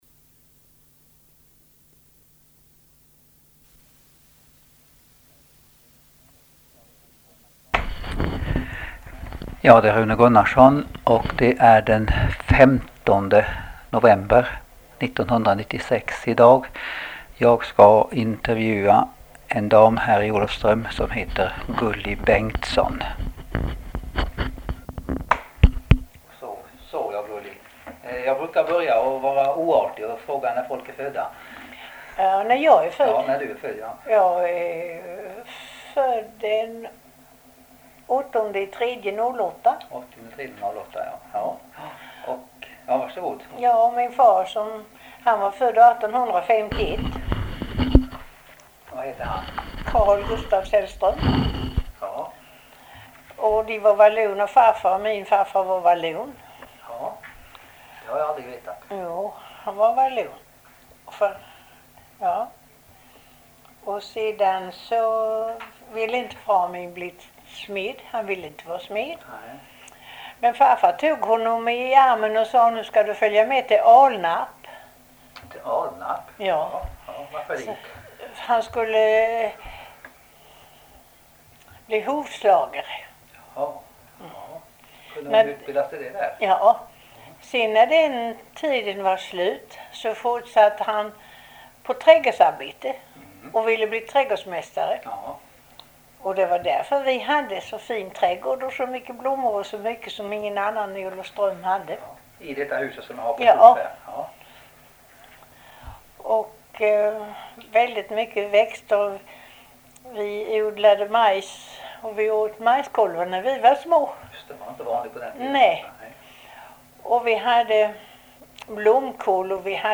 Intervjuer om Holje by - Arkiv & Forskning i Skåneland